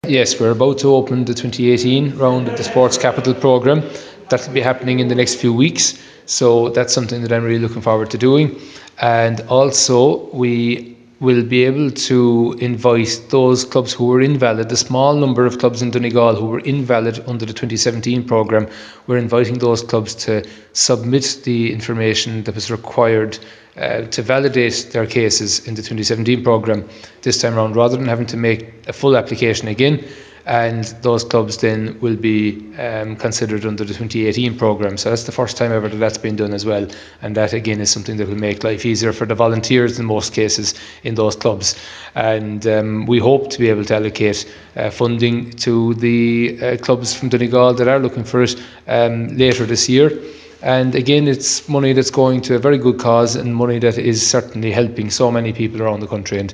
Minister Griffin said a new round of funding will be announced later in 2018 – and he’s hoping a number of clubs and organisations in Donegal will benefit again…